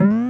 Hit no damage.
Boing_(DKC2).oga.mp3